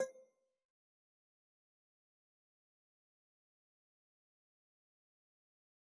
cursor_style_2.wav